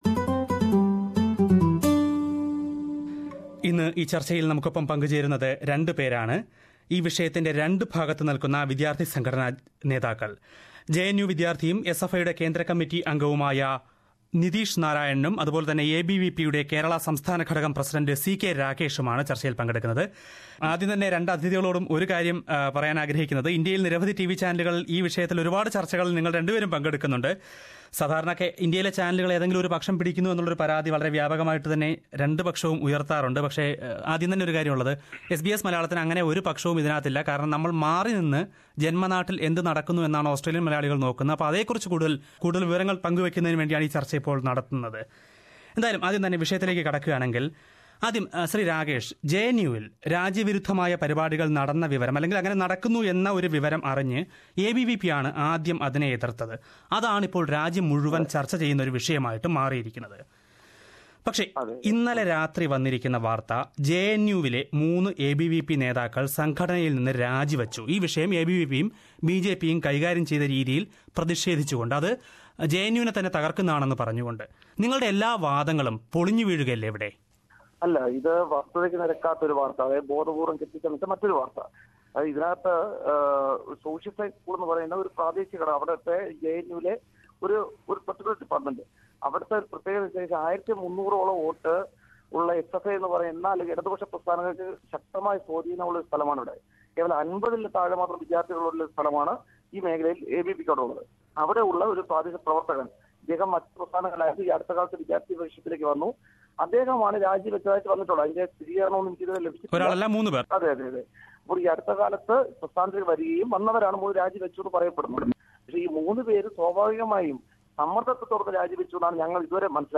Whats happening in JNU: A discussion
When JNU is in news internationally, SBS Malayalam is conducting a political discussion on the subject.